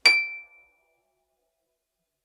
healing-soundscapes/Sound Banks/HSS_OP_Pack/Harp/KSHarp_D7_f.wav at main
KSHarp_D7_f.wav